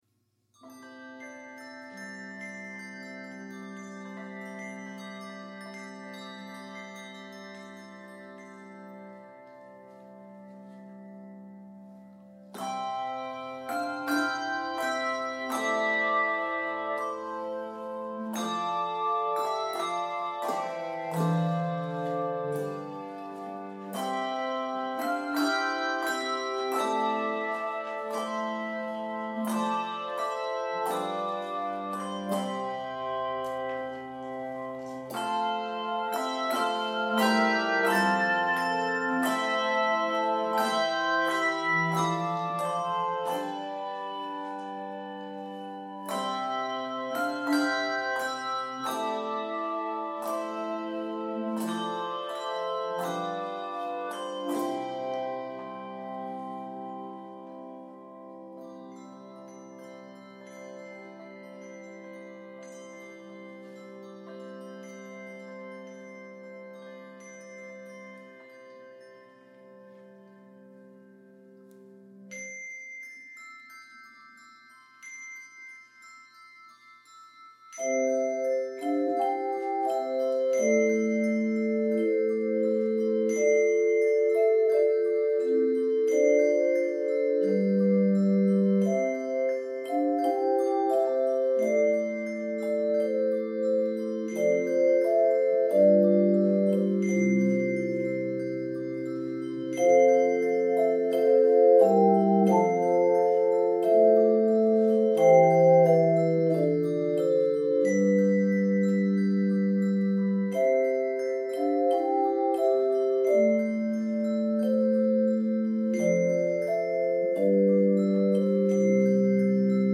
This is a beautifully quiet, charming setting of the carol